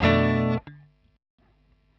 Bm7_2.wav